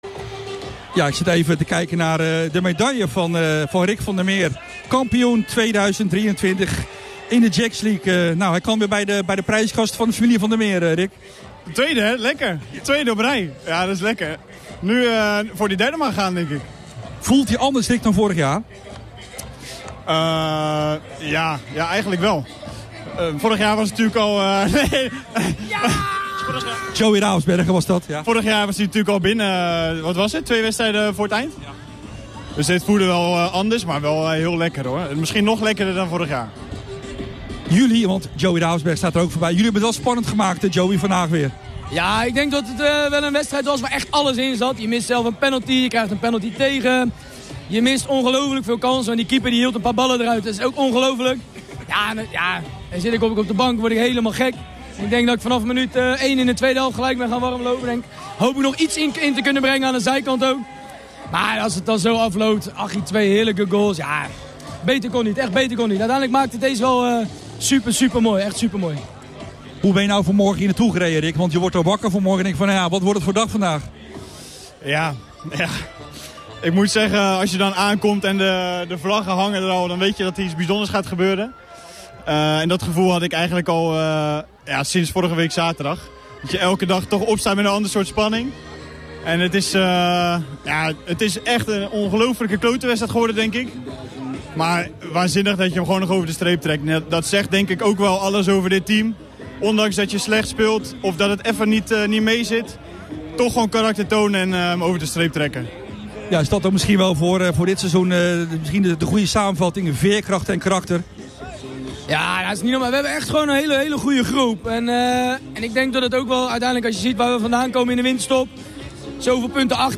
Ook onze mediapartner RTV Katwijk pakte zaterdag flink uit op de Krom na het kampioenschap van vv Katwijk.